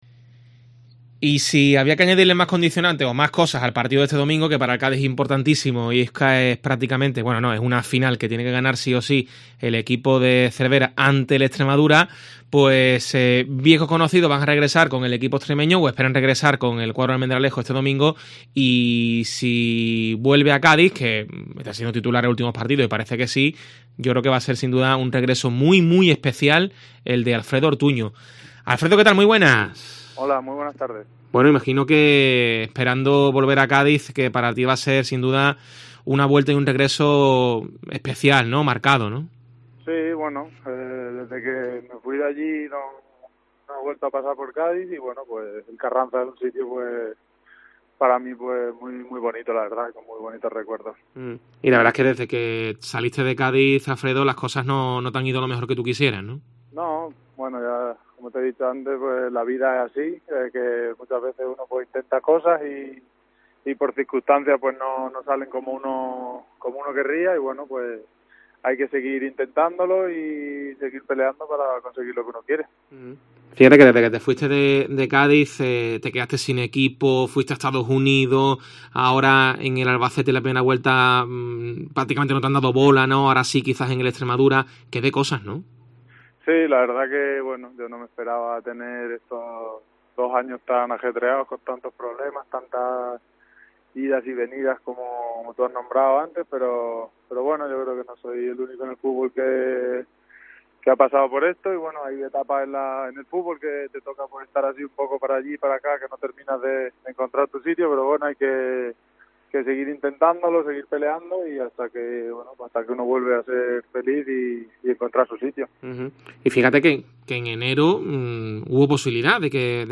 Entrevista al exjugador del Cádiz